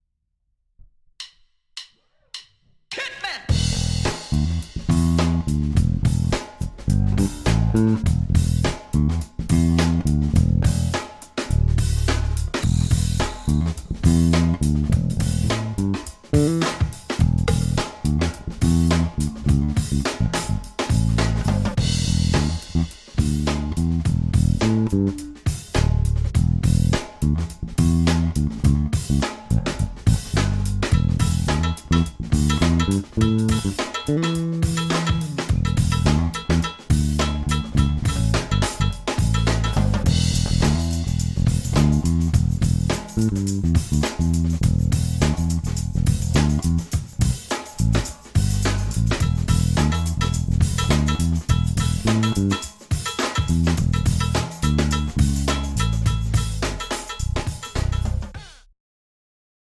"Jazz Bass" nastavení